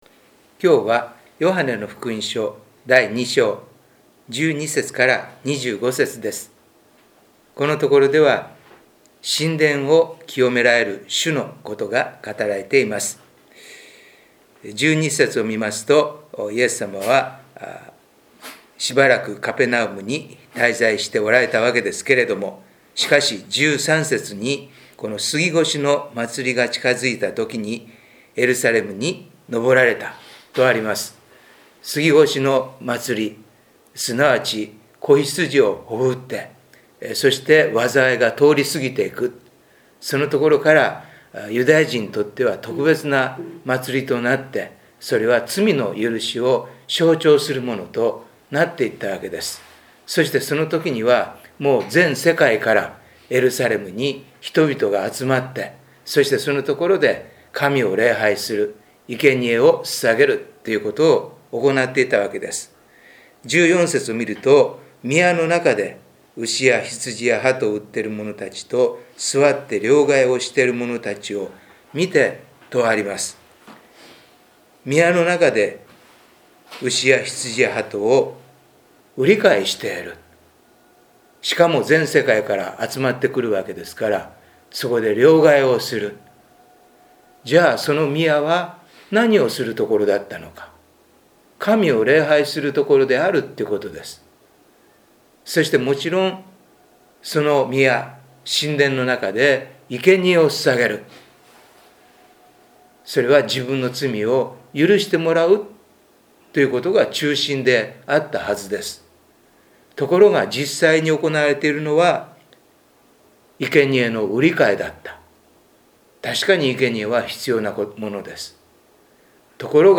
デボーションメッセージ│日本イエス・キリスト教団 柏 原 教 会